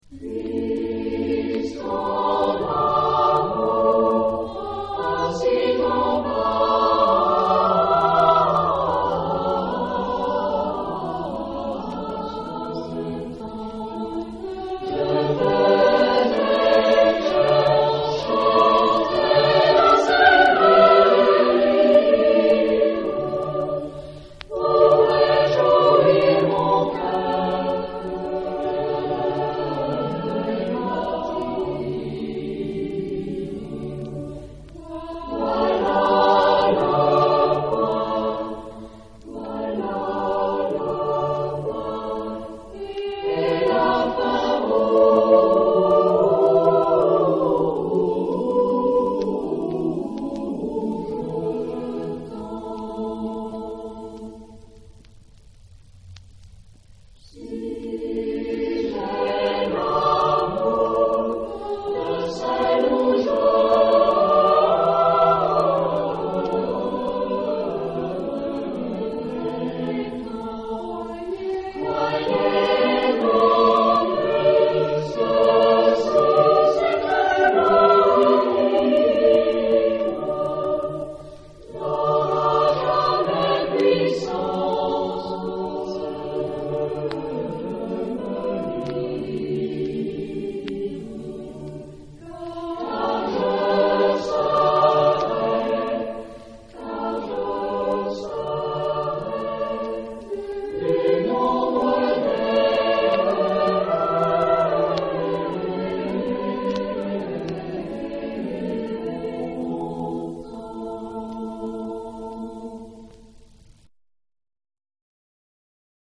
Genre-Style-Form: Secular ; Partsong ; Renaissance
Type of Choir: SATB  (4 mixed voices )